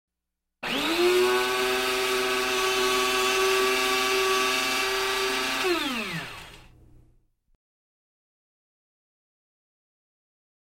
Кофемолка
Тут вы можете прослушать онлайн и скачать бесплатно аудио запись из категории «Бытовая техника».